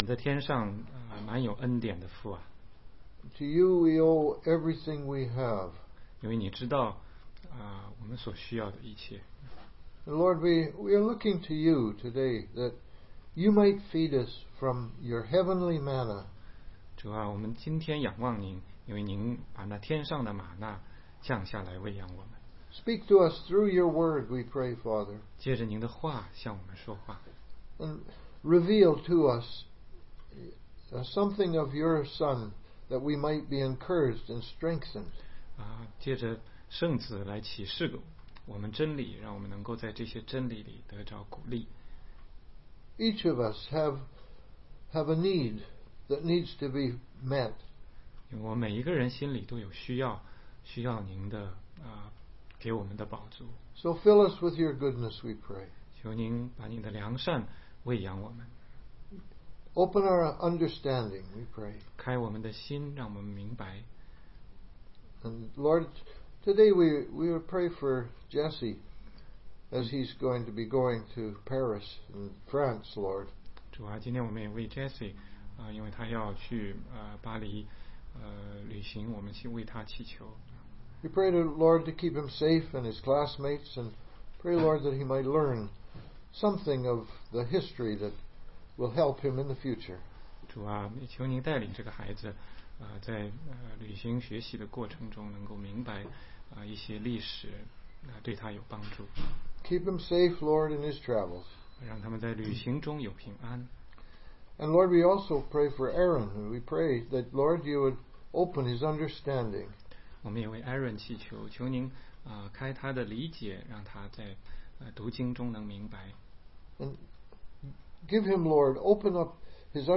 16街讲道录音 - 约翰福音7章8-15节